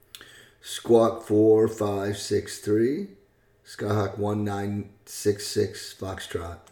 Aviation Radio Calls